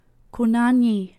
And they gave as a pronunciation guide ”ku nah nyee” and published online a recording of it by a native speaker:
(ku nah nyee)